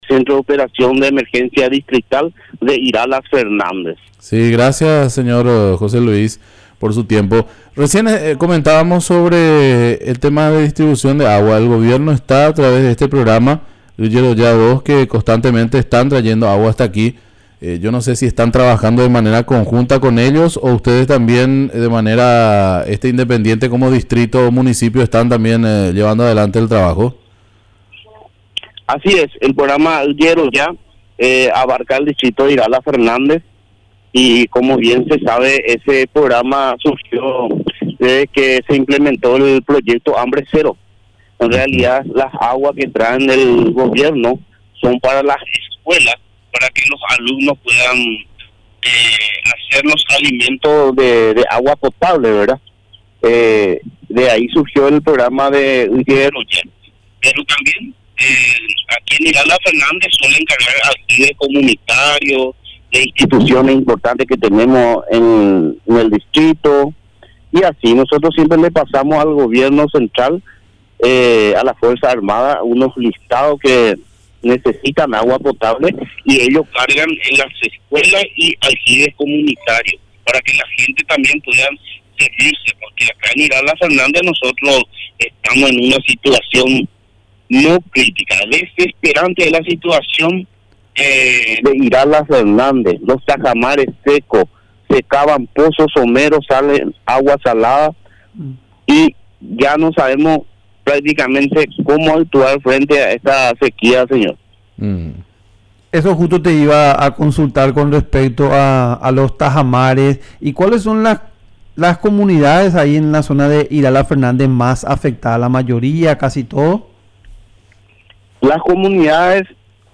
Entrevistas / Matinal 610 Distribución de agua Oct 15 2024 | 00:11:31 1x 00:00 / 00:11:31 Subscribe Share RSS Feed Share Link Embed